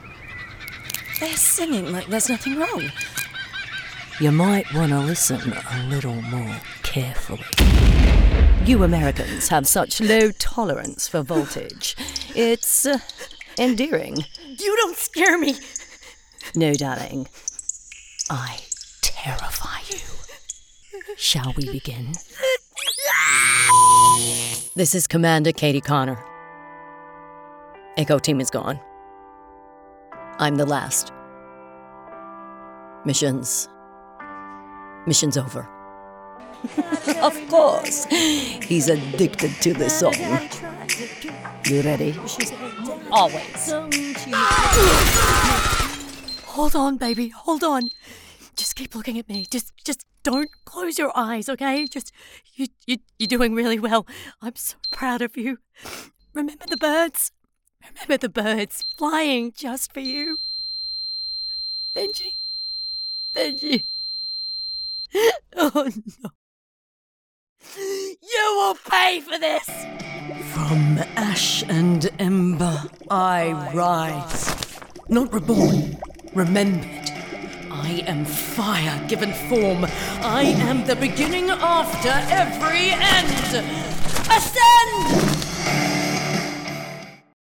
Female
English (British), English (Australian)
Yng Adult (18-29), Adult (30-50)
Commercial vibes: From warm, intimate and inviting to cool, confident and charismatic all authentic, conversational and believable.
Animation & Video Games: Compassionate Leader, Faithful Soldier, Fearful Demon, Cute Critter, Comanding Queen
UK/AUS/US
Video Game & Animation Demo